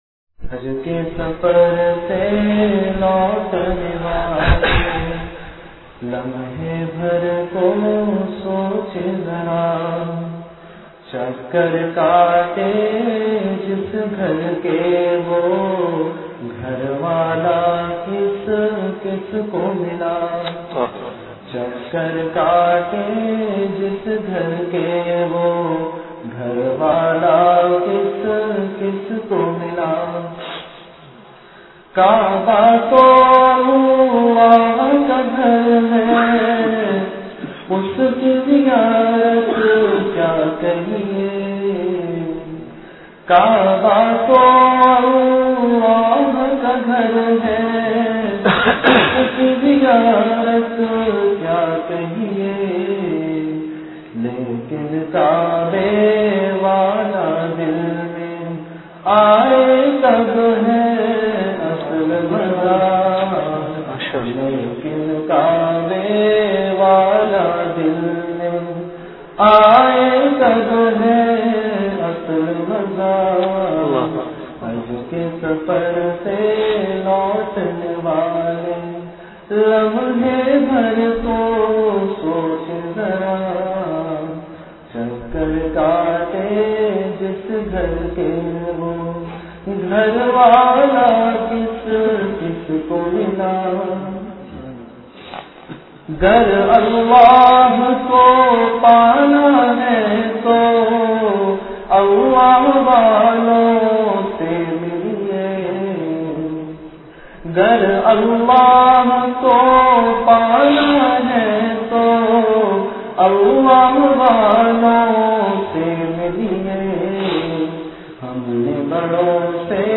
Majlis-e-Zikr
After Isha Prayer